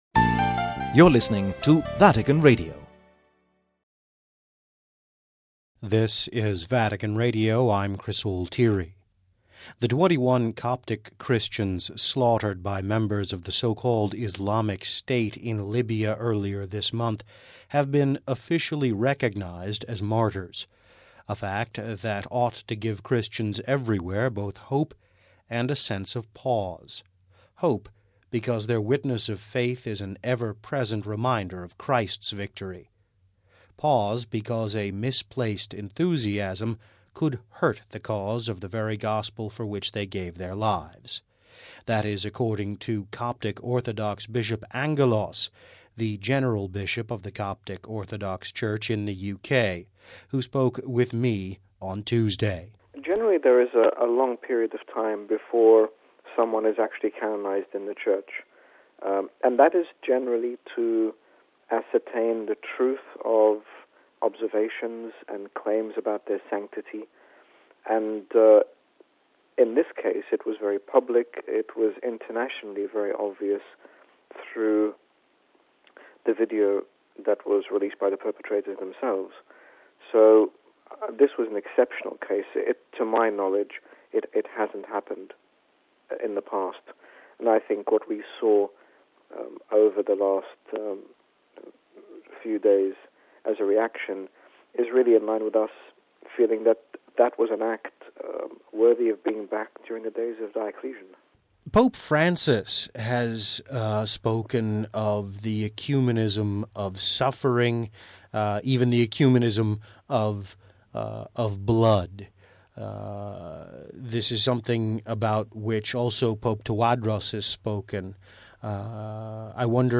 BBC Three Counties Radio interviews His Grace Bishop Angaelos, General Bishop of the Coptic Orthodox Church regarding the Orthodox celebration of Christmas, also touching on the persecution of Christians in the Middle East Download Audio Read more about BBC 3 Counties Radio Interview with HG Bishop Angaelos re Orthodox Christmas